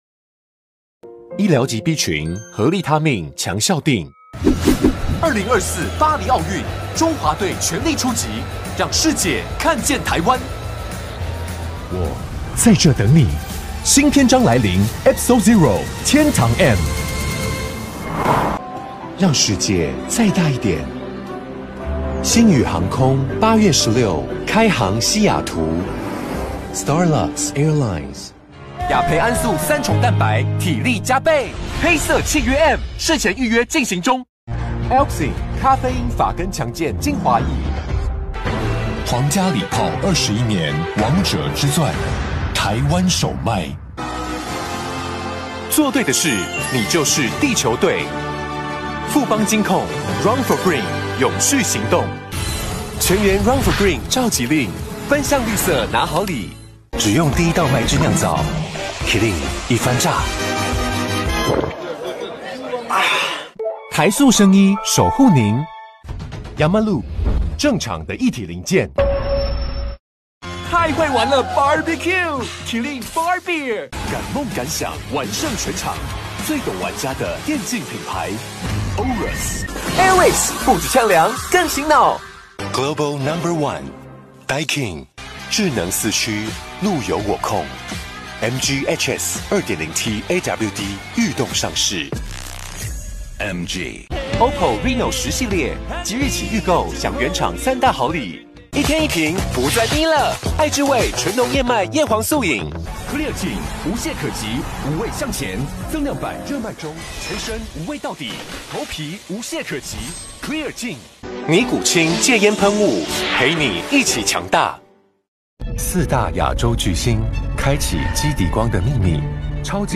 國語配音 男性配音員
廣告配音